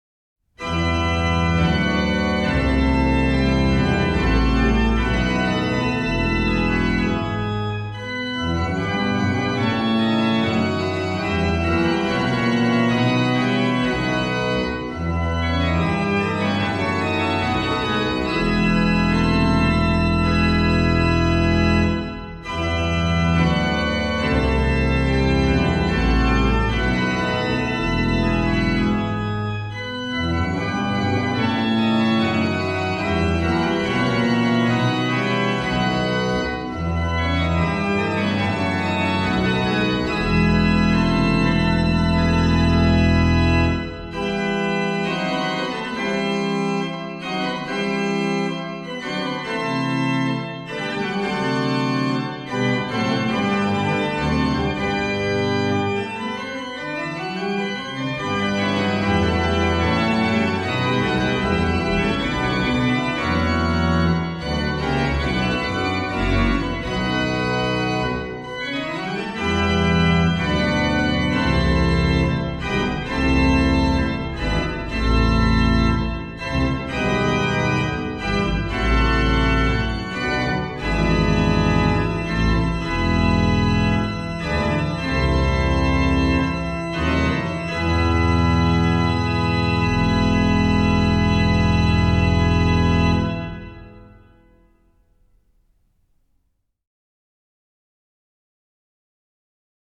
Registration   OW: Pr16, Oct8, Oct4, Mix
Ped: Oct8, Oct4, Mix, Pos16